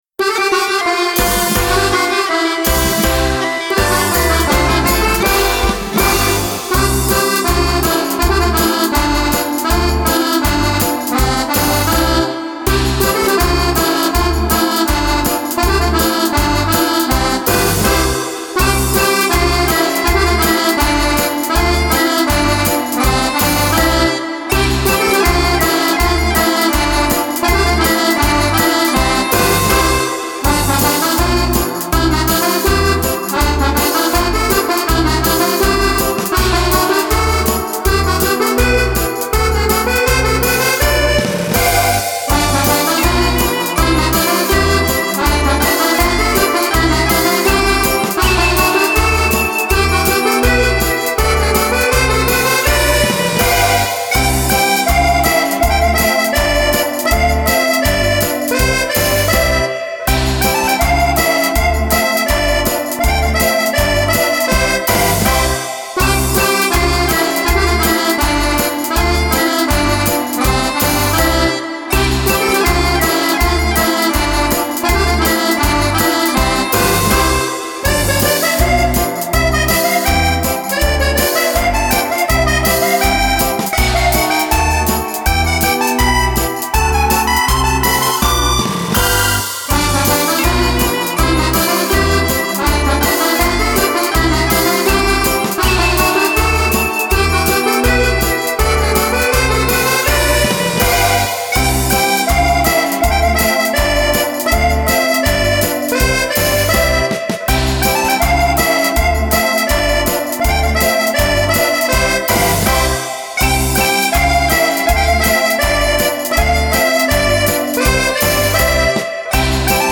version en DO